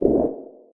Sfx_creature_penguin_waddle_voice_05.ogg